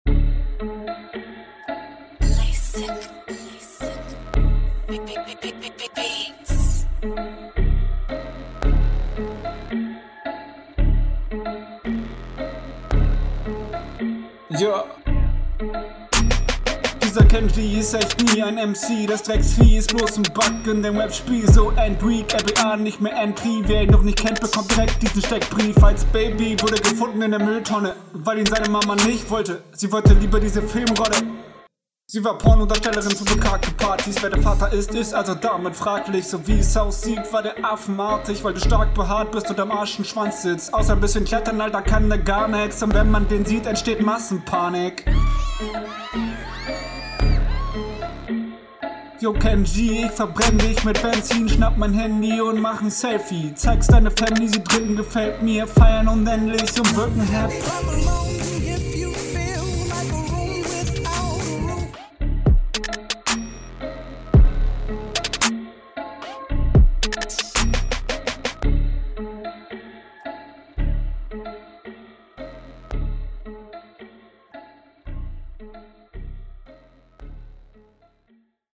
Einstieg kommt etwas komisch. Wirkt irgendwie nicht ganz sicher auf dem Beat.
spinn ich oder knackt deine audio hier und da - liegt das am beat oder …